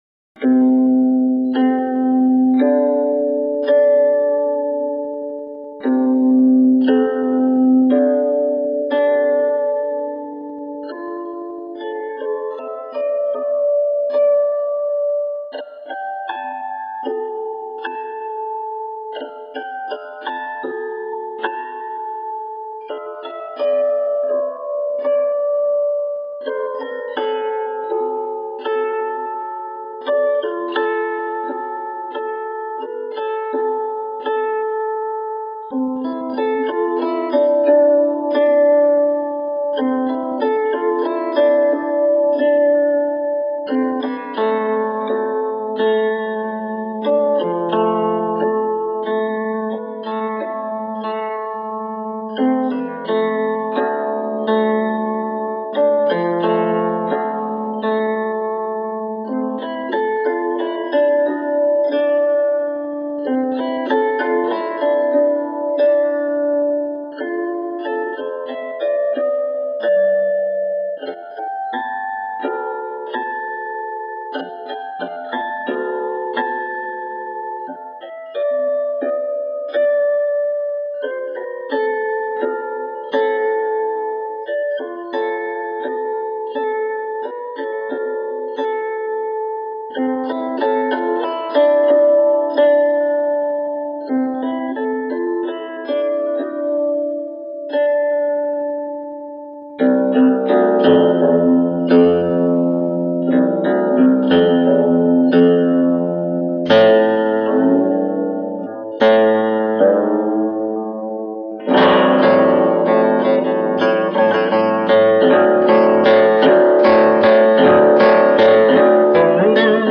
0195-古琴曲神人畅.mp3